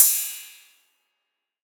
808CY_8_TapeSat_ST.wav